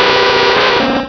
Fichier:Cri 0169 DP.ogg — Poképédia
Cri de Nostenfer dans Pokémon Diamant et Perle.